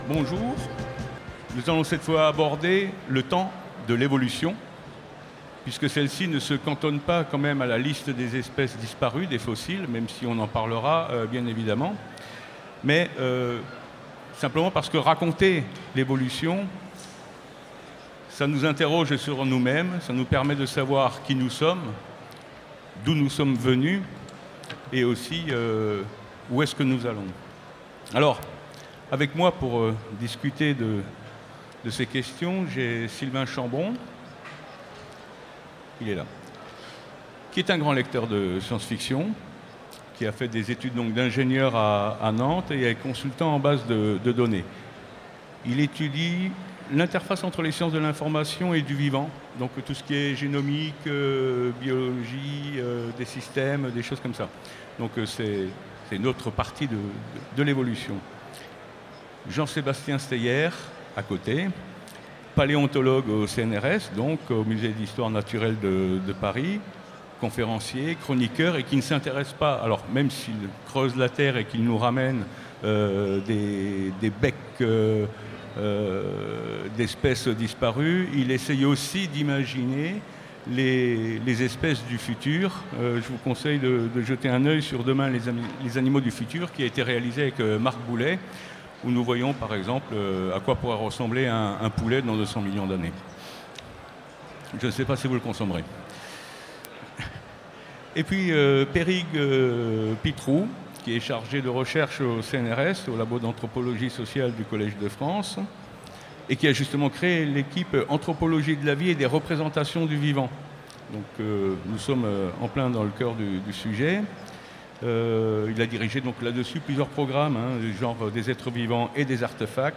Utopiales 2017 : Conférence Le temps de l’évolution